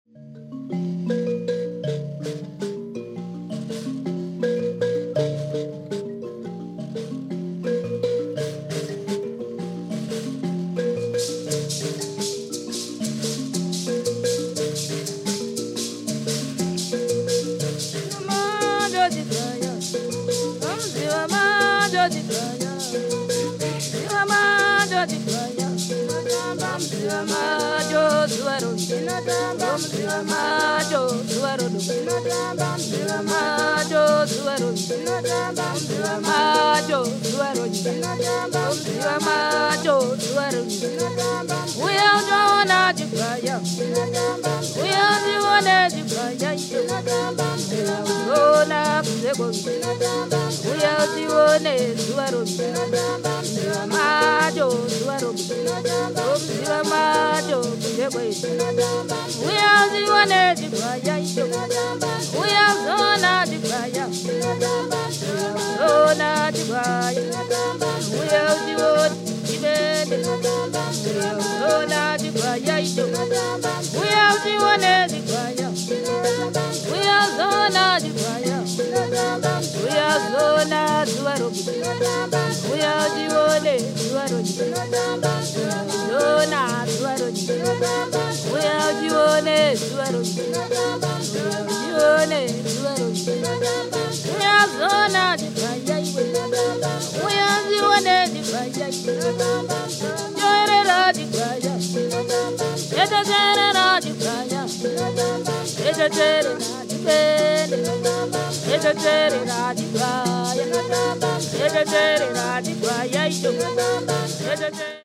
mbira
hosho (shaker)